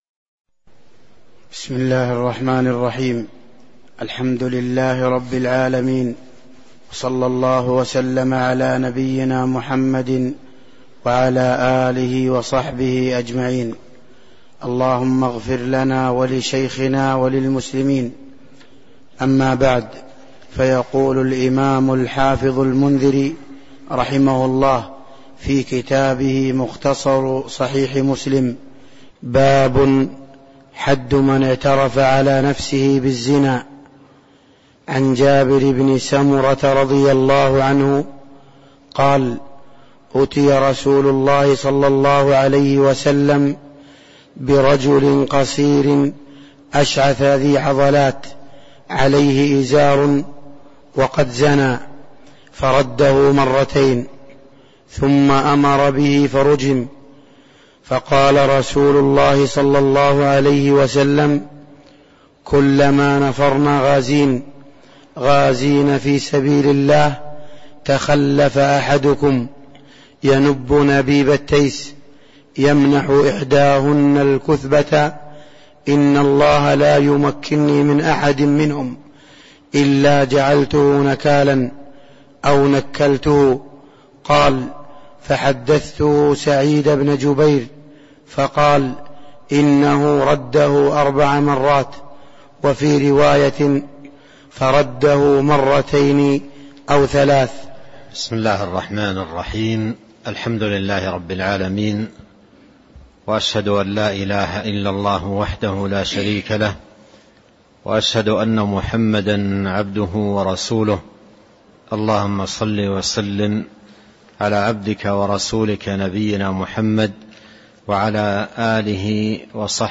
تاريخ النشر ٧ ربيع الأول ١٤٤٣ هـ المكان: المسجد النبوي الشيخ